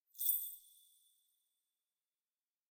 footstep_ghost_4.ogg